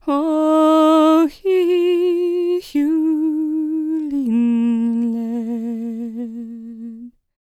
L  MOURN A03.wav